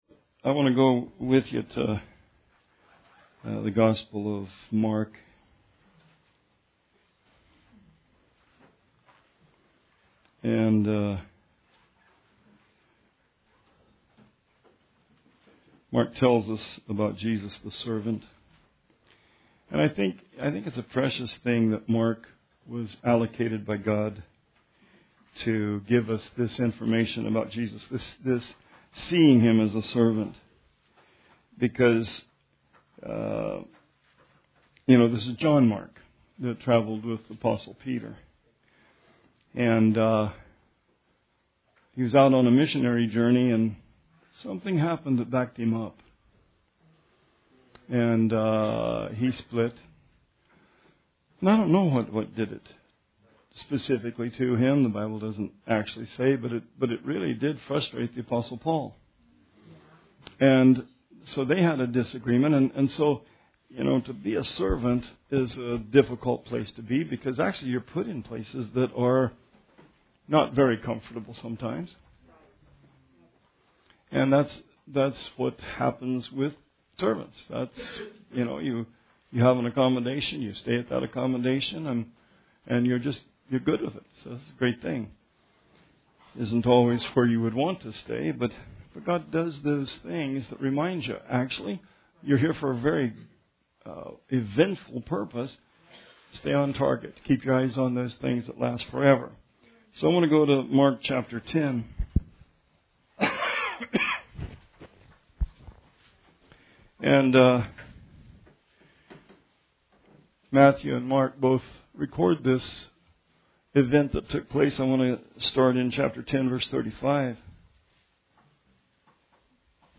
Sermon 12/29/18